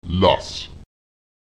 Lautsprecher las [las] das Schriftzeichen